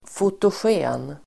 Ladda ner uttalet
Uttal: [fotosj'e:n]